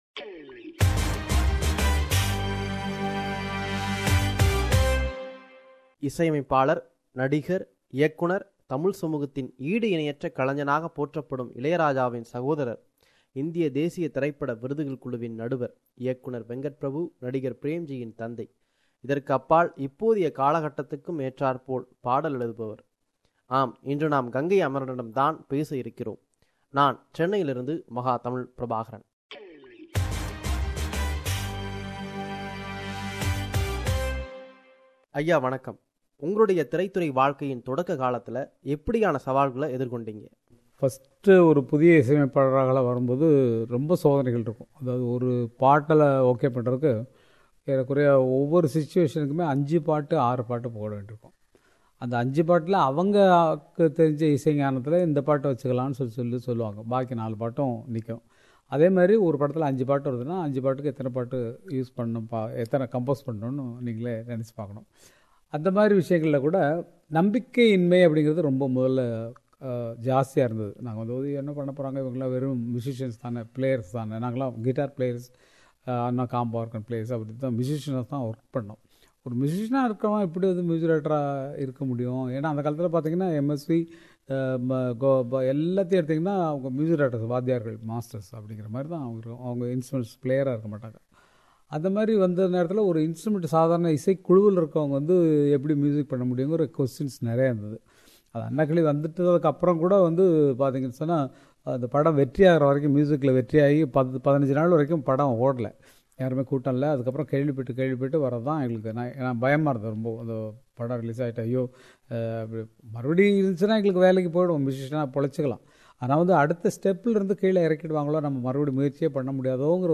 An exclusive with Gangai Amaran – Part 1